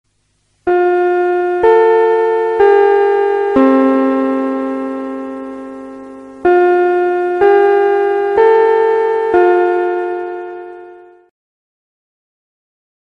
Suara Bel Sekolah Jepang
Kategori: Suara bel berbunyi
Keterangan: Suara bel yang khas dan unik ini cocok digunakan di semua jenis HP, memberikan nuansa sekolah Jepang yang otentik di setiap momen atau proyek video yang Anda buat.
suara-bel-sekolah-jepang-id-www_tiengdong_com.mp3